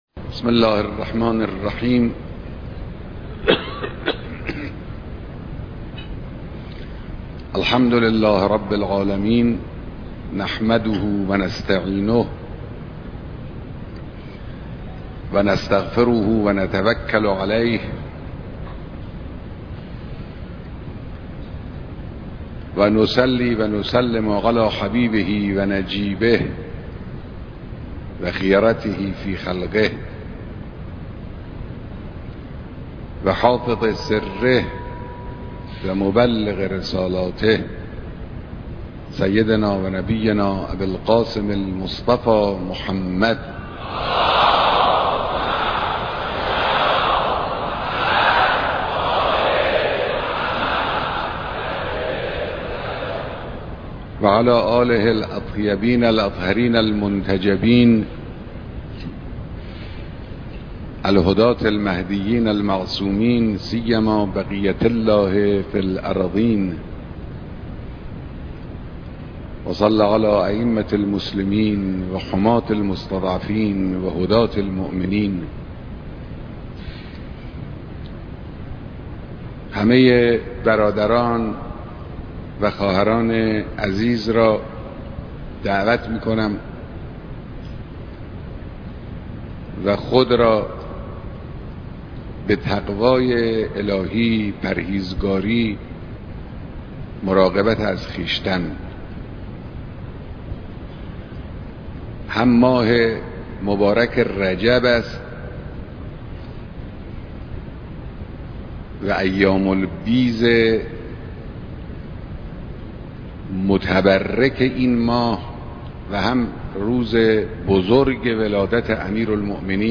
اقامه نماز جمعه تهران به امامت رهبر معظم انقلاب اسلامي